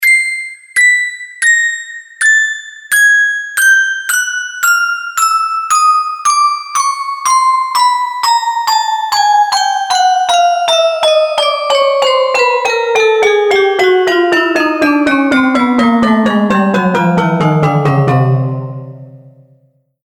| glockenspiel chord |